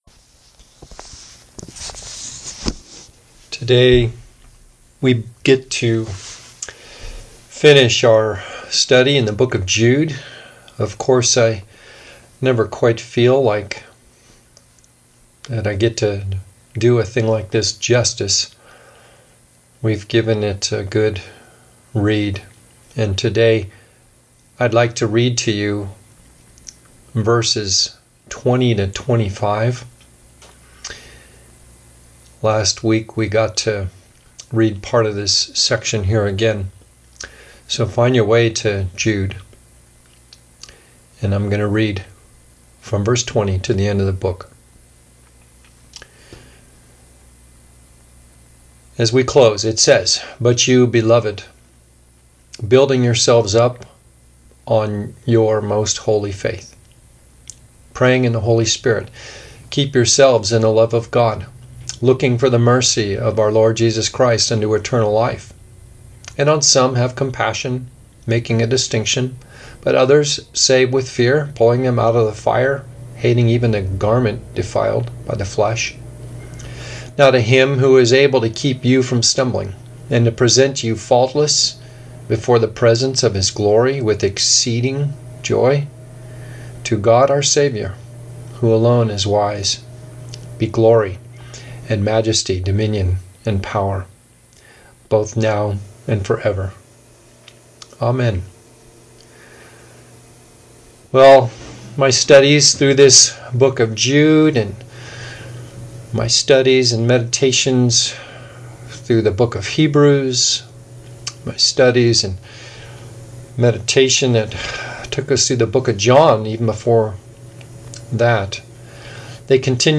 4/5 – Sermon – Glorious Faith – Jude 24-25 – The Dome Church In Laytonville